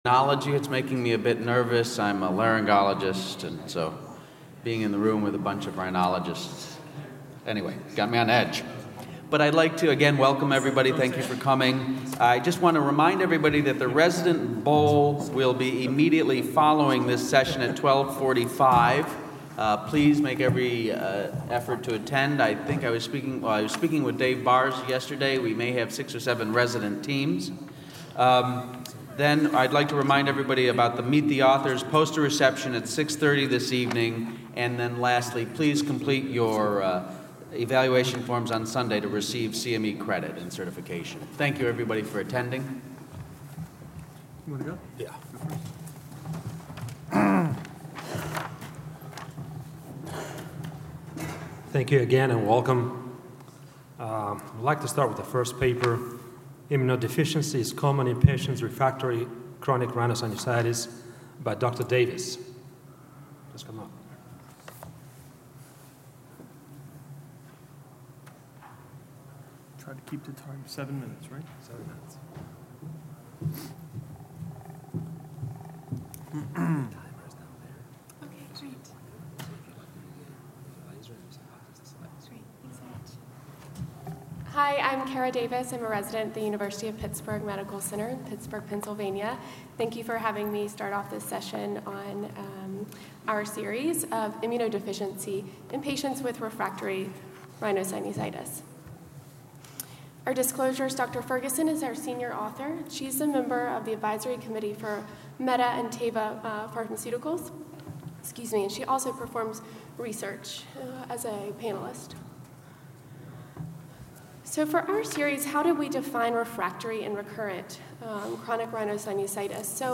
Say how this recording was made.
Experts at the Triological Society’s 2014 Combined Sections Meeting present research in rhinology.